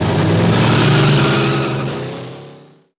peelout.wav